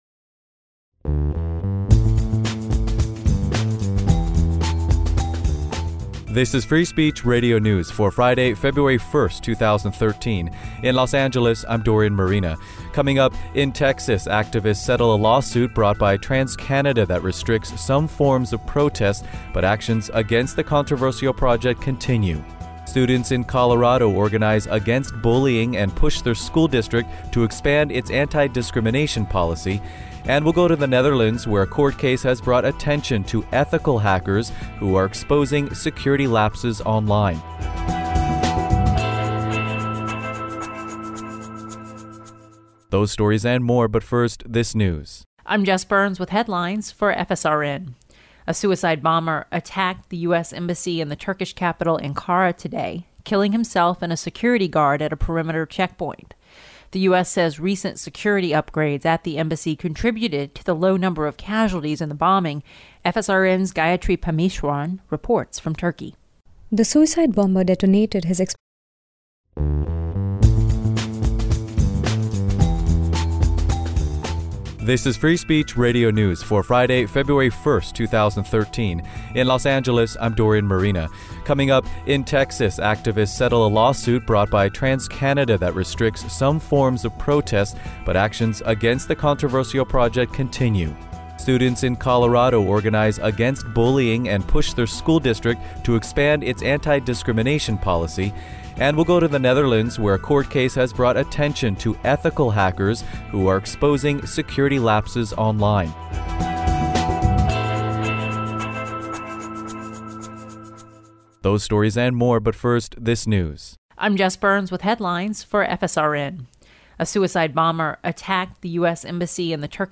Newscast for Friday, February 01, 2013